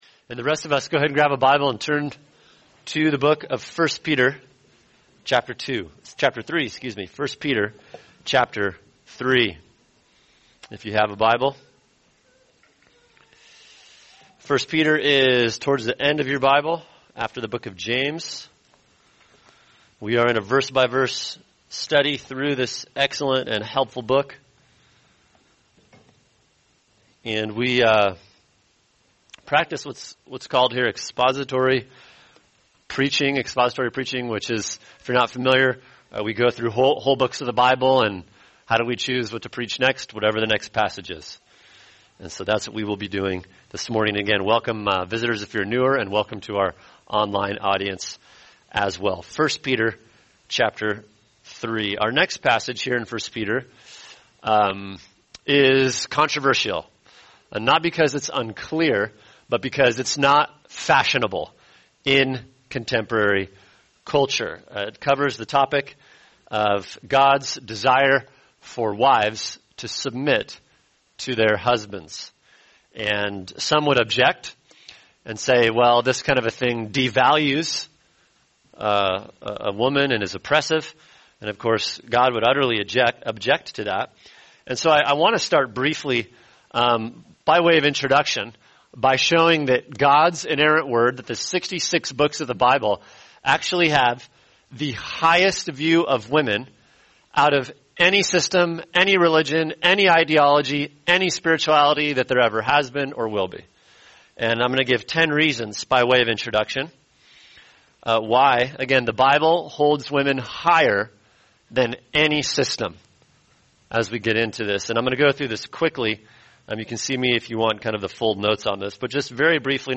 [sermon] 1 Peter 3:1-6 God’s High Call for Wives | Cornerstone Church - Jackson Hole